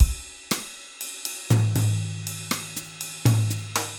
Mambo 1
Straight / 120 / 2 mes
MAMBO1 - 120.mp3